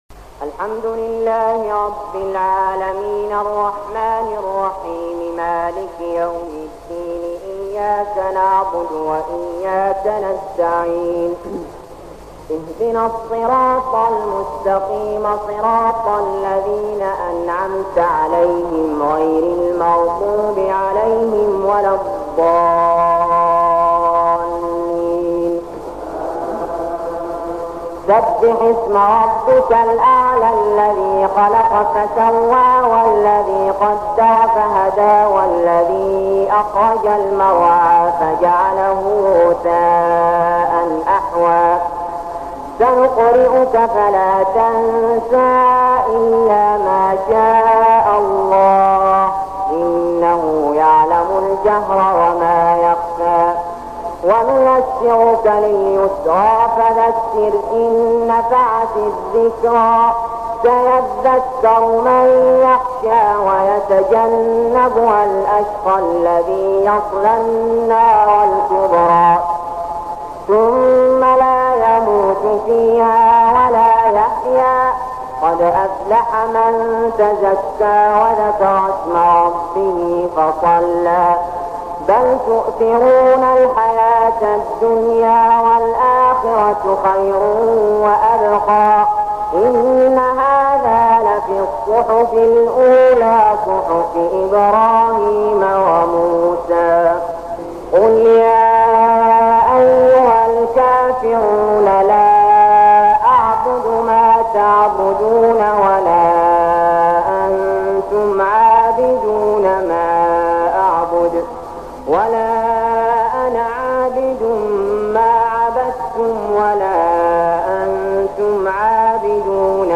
من النوادر الشفع والوتر مع الدعاء للشيخ علي جابر ١٤٠١هـ > تراويح الحرم المكي عام 1401 🕋 > التراويح - تلاوات الحرمين